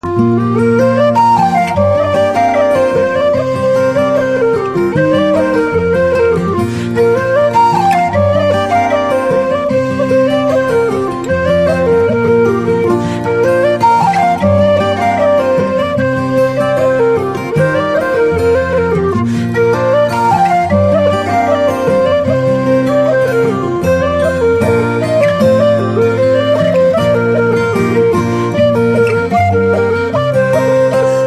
(Slow Reels)